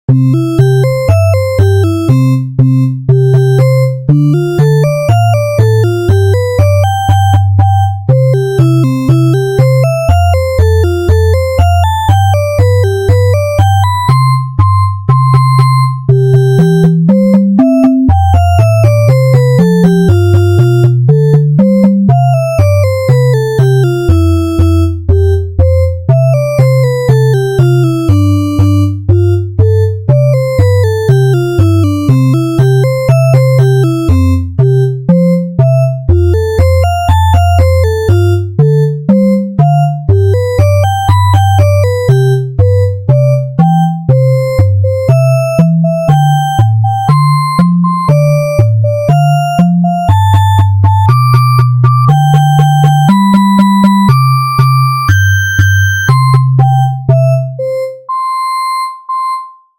明るい街、大きい街のイメージ。
BPM120
明るい
軽快